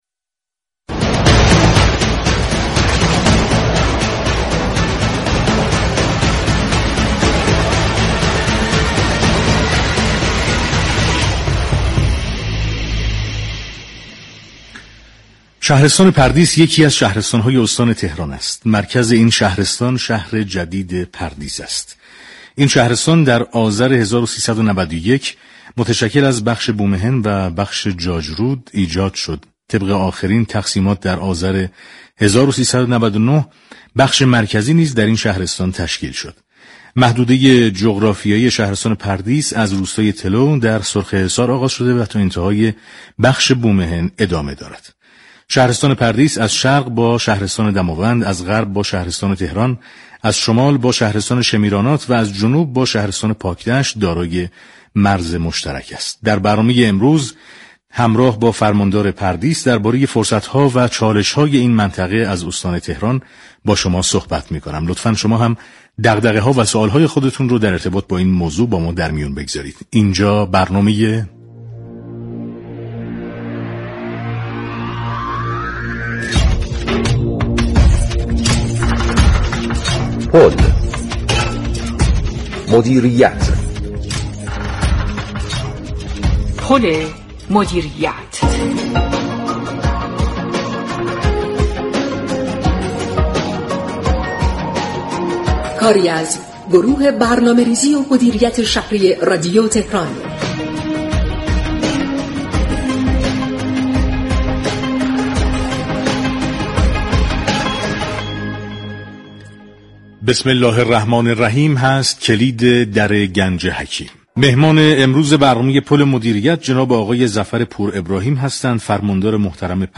در همین راستا برنامه پل مدیریت 26 بهمن با موضوع مسائل این شهرستان و با حضور ظفر پور ابراهیم فرماندار این شهرستان بر روی آنتن رفت.